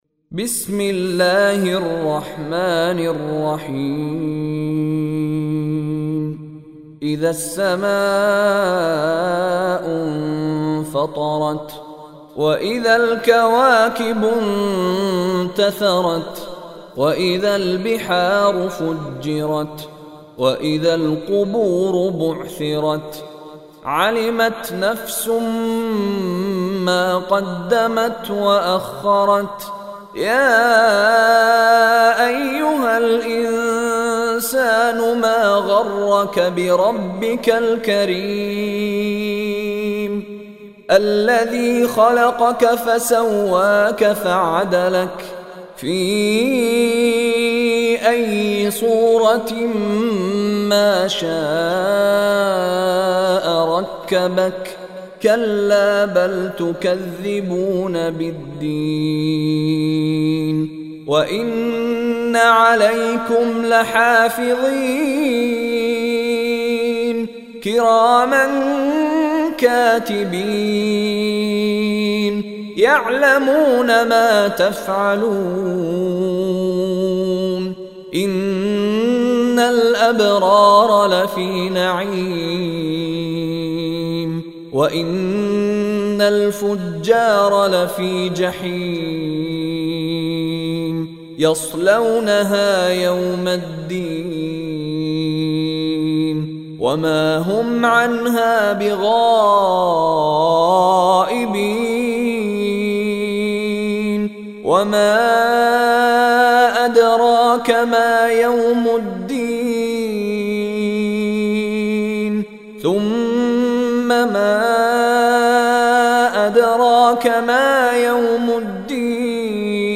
Listen online and download Surah Infitar mp3 tilawat / recitation in the voice of Sheikh Mishary Rashid Alafasy.